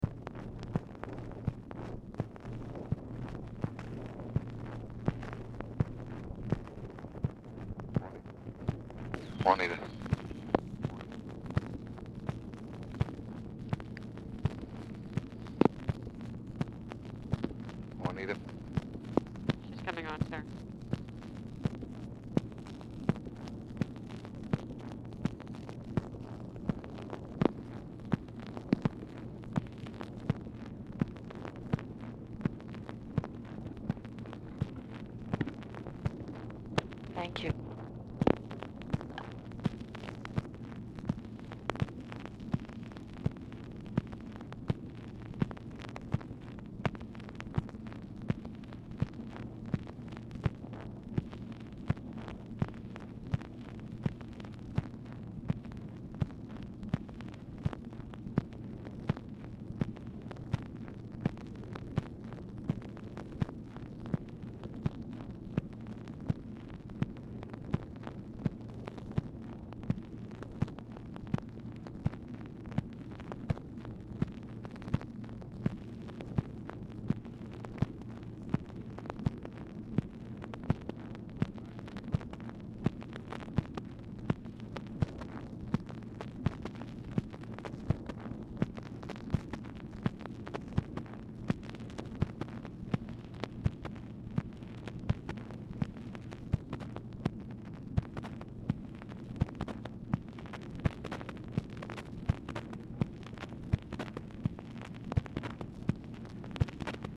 Telephone conversation # 5240, sound recording, LBJ and TELEPHONE OPERATOR, 8/26/1964, time unknown | Discover LBJ
Format Dictation belt
Location Of Speaker 1 Oval Office or unknown location